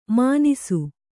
♪ mānisu